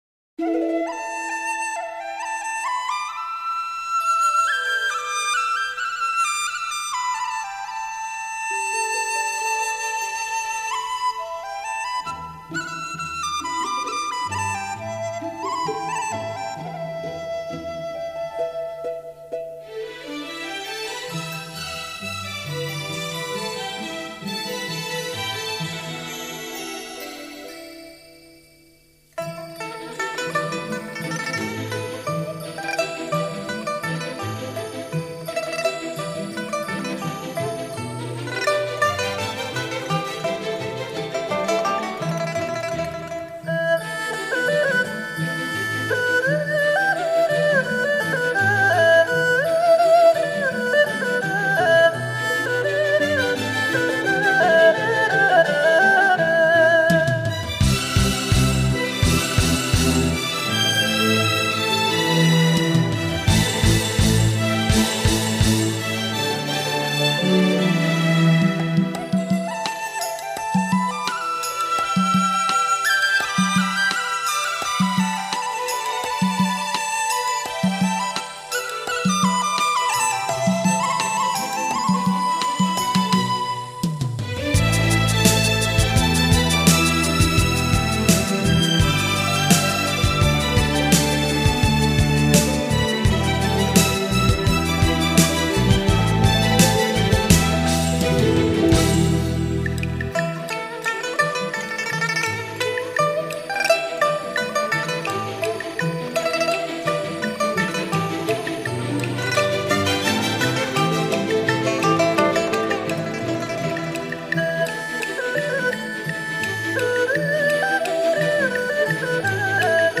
纯音乐